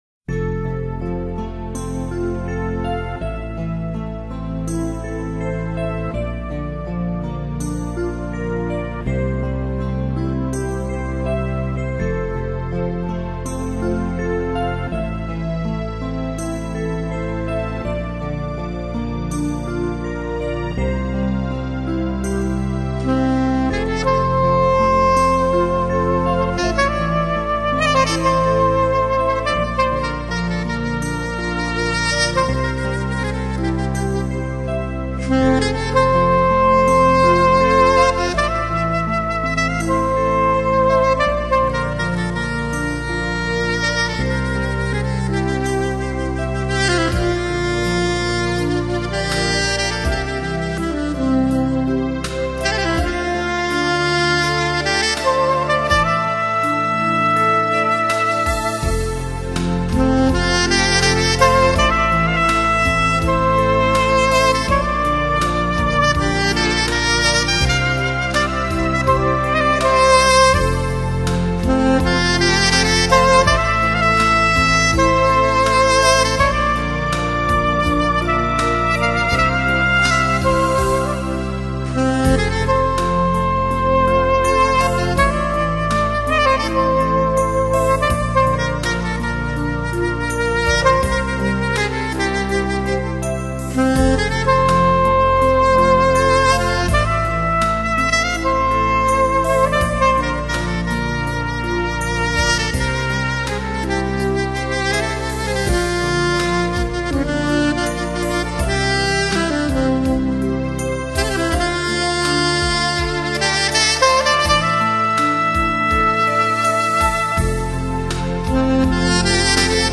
整张碟总的风格可以说是"柔情似水"，令人不觉沉醉。
Soprano Saxophone圆润多变的音色，在这一曲中发挥得淋漓尽致。
平稳的调子象在感叹爱的温馨；圆润甜美的高音又让人感到爱的激动；而乐曲的婉转之处，是爱的缠绵。